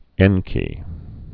(ĕnkē)